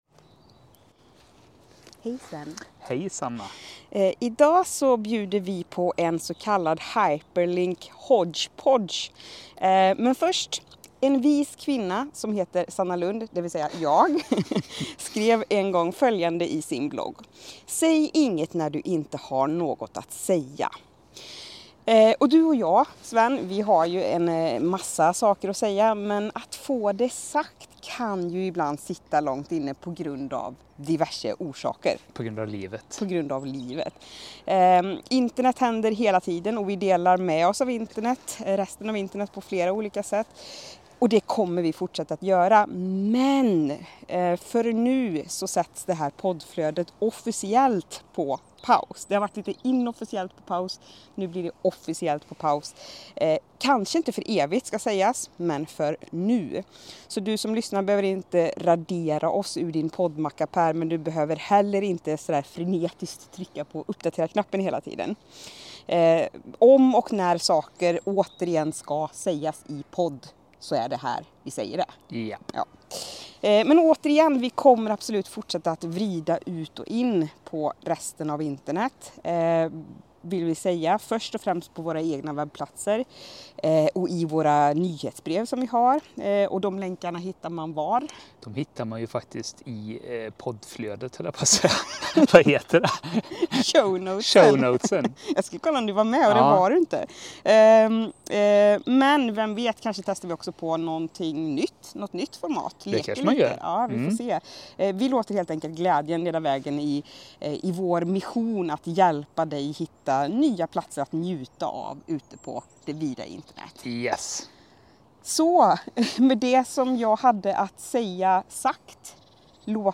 Från en solvarm klippa i Jönköpings stadspark, omgivna av fågelkvitter och skrikande barn, dricker vi kaffe ur termos och äter inte bara en, utan två bullar. Men innan vi njuter av dem delar vi ett gäng länkar med dig.